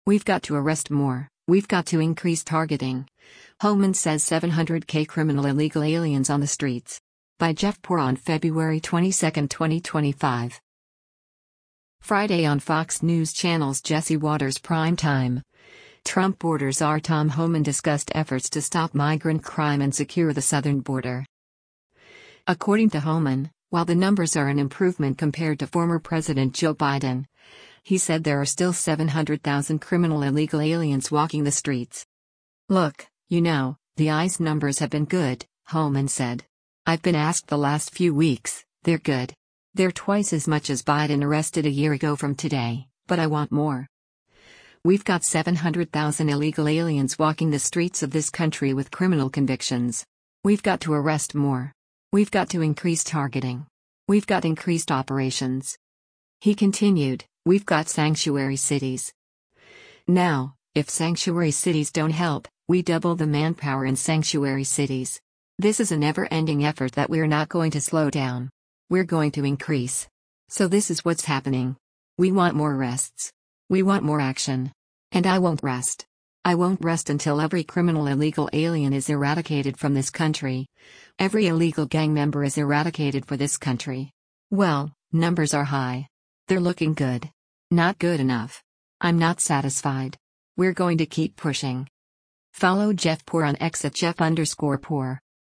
Friday on Fox News Channel’s “Jesse Watters Primetime,” Trump border czar Tom Homan discussed efforts to stop migrant crime and secure the southern border.